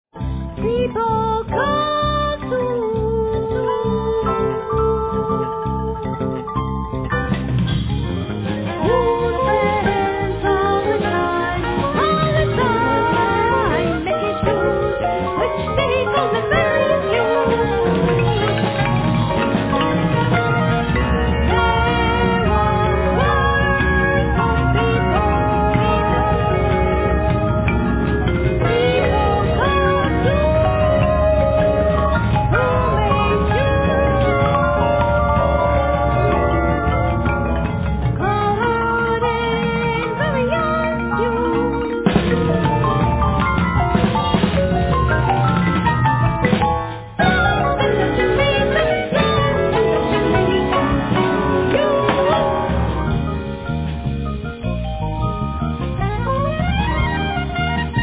Vocals
Soprano+Tenor Sax
Yamaha Grantouch(Piano)
Marimba,Korg 01W,Programming & conducting
5-string e.bass
Drums